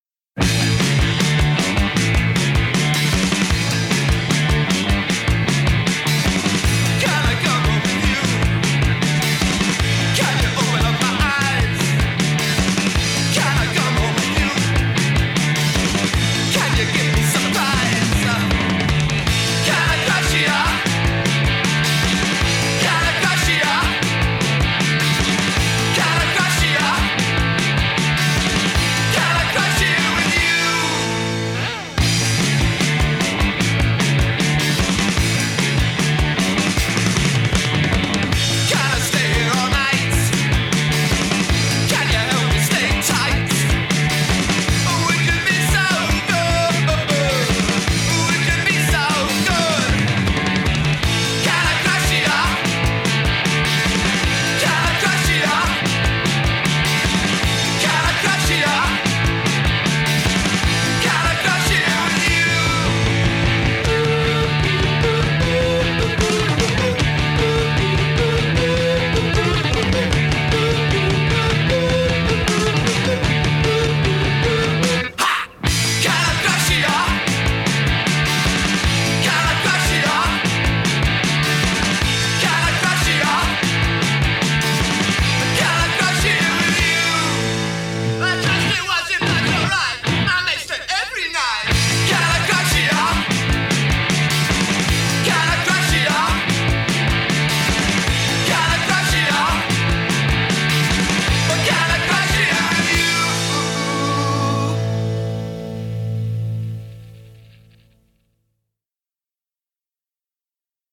Very fun punky power/pop...just the way I like it.